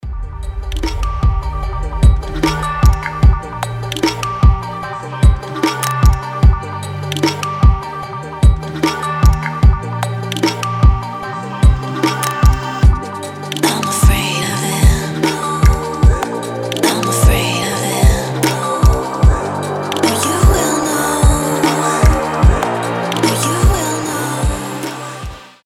• Качество: 320, Stereo
атмосферные
мелодичные
Electronic
нарастающие
Стиль: trap